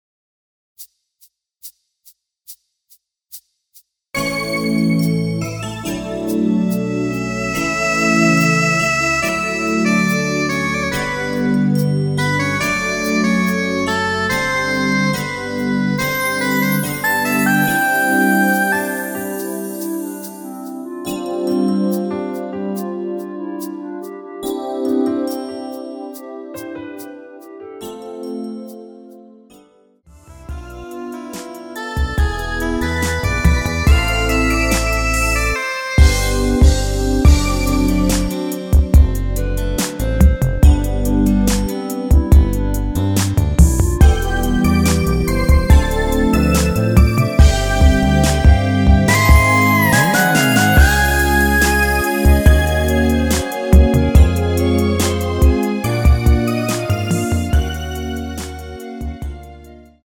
원키에서(-1)내린 2절부분 삭제한 멜로디 포함된 MR입니다.
앞부분30초, 뒷부분30초씩 편집해서 올려 드리고 있습니다.
중간에 음이 끈어지고 다시 나오는 이유는